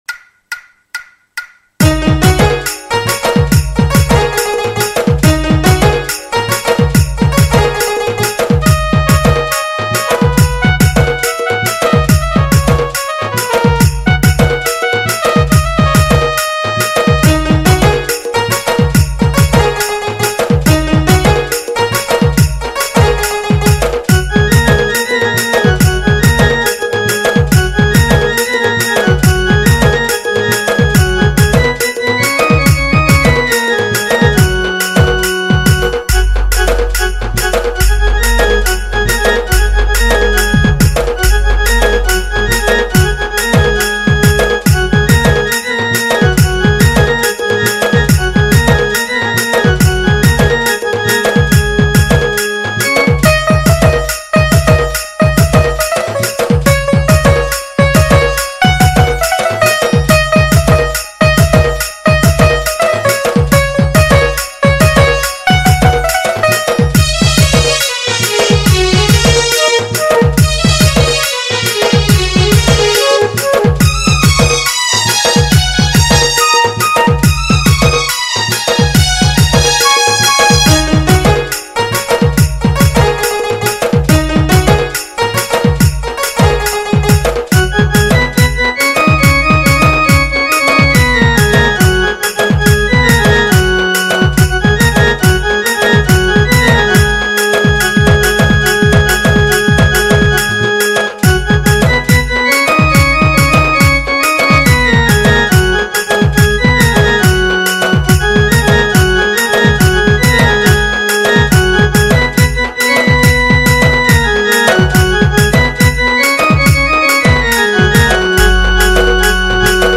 Instrumental Music And Rhythm Track